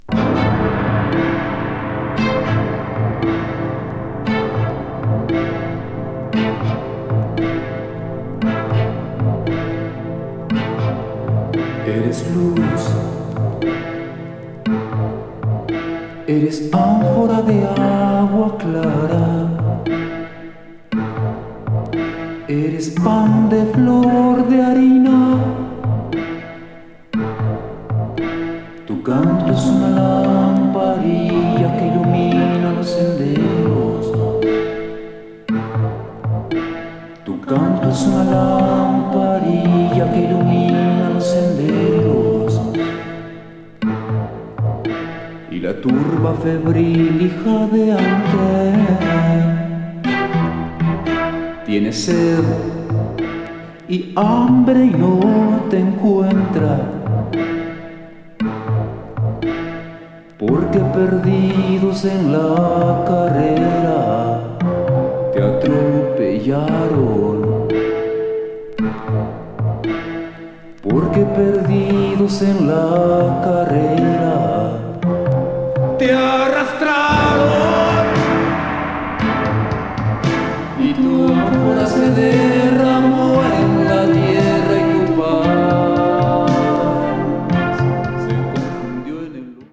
vocals, guitar, keyboards
drums, electronics